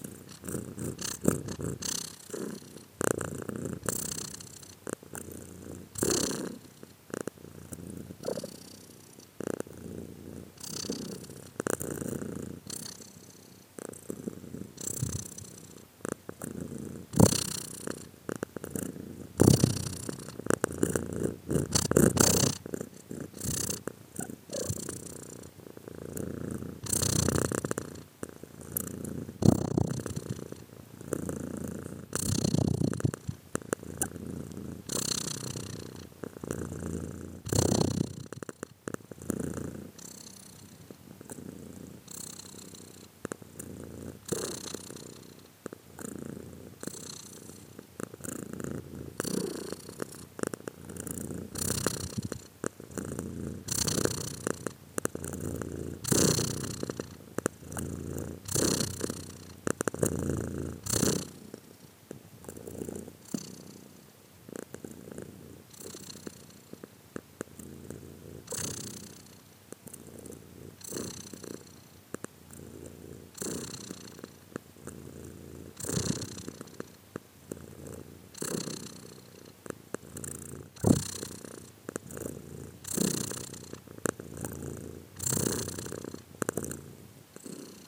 animals / cats